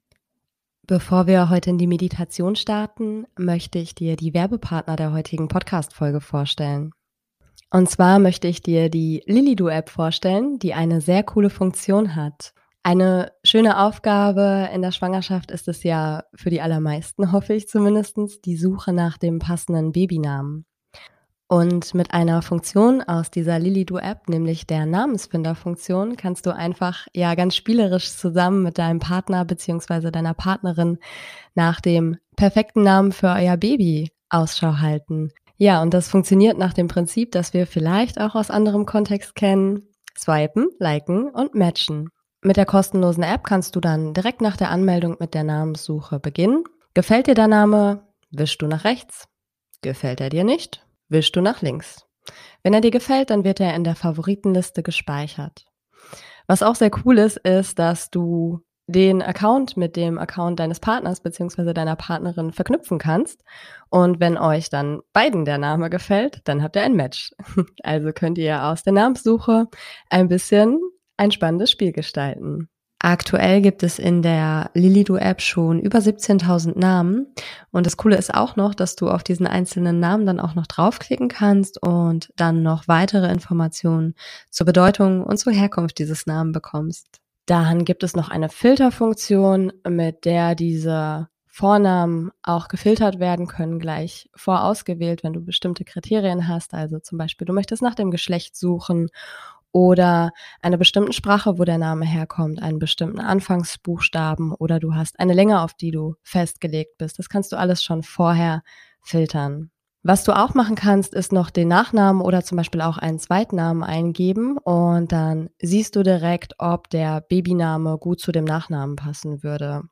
Daher habe ich mit dieser Meditation eine kleine Reinigungsübung geschaffen. Sie soll dir zunächst einmal den Raum geben, die überhaupt klar zu werden, darüber, was dir vielleicht nicht gut tut an Glaubenssätzen.